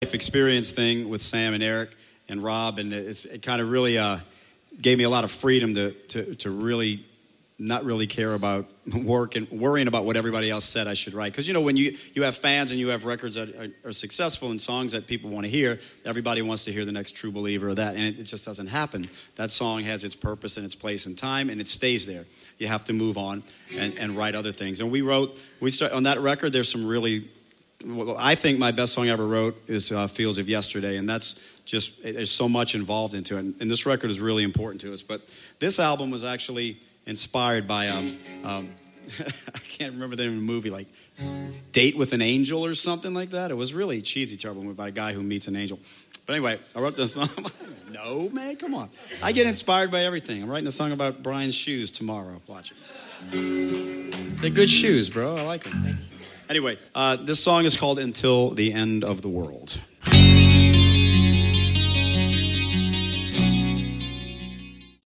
Category: Hard Rock
lead vocals
bass
drums
acoustic guitar, vocals